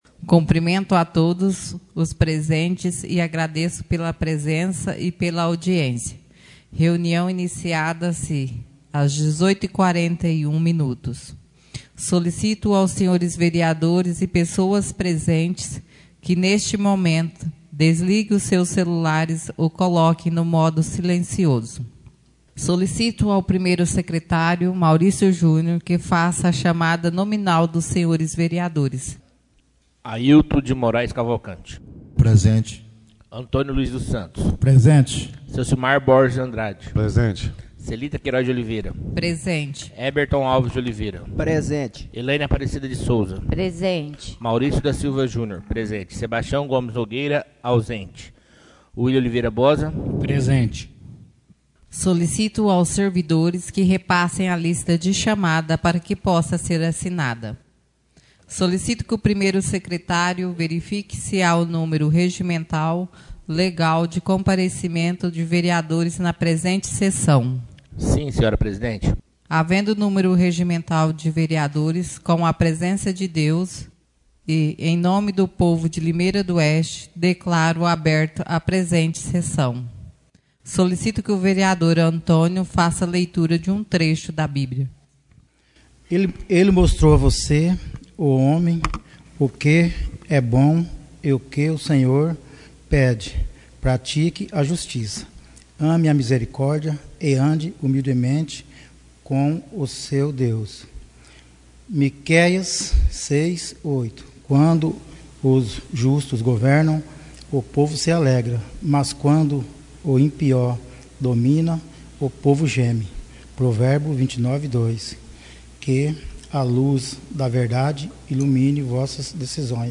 Reuniões Extraordinárias